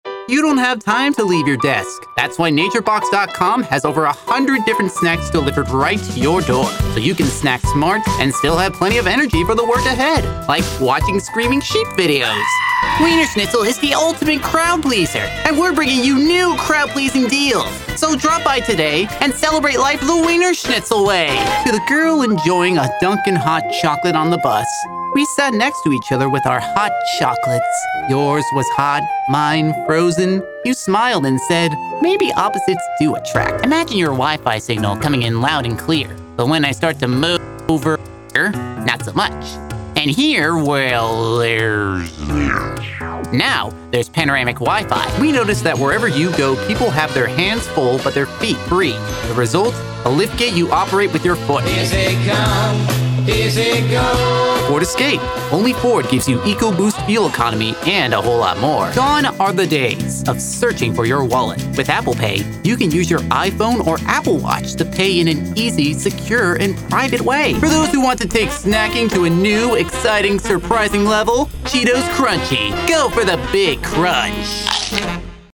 Teenager, Child, Young Adult
Has Own Studio
Accents:
british english
southern us
standard us
Commercial_demo.mp3